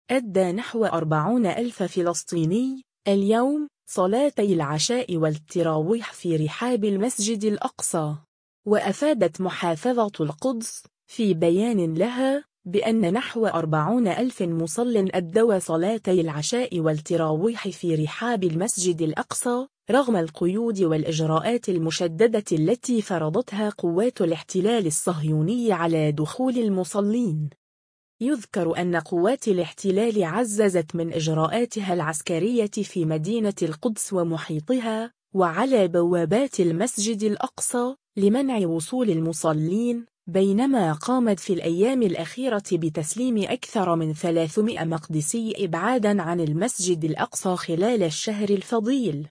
أدّى نحو 40 ألف فلسطيني، اليوم، صلاتي العشاء والتّراويح في رحاب المسجد الأقصى.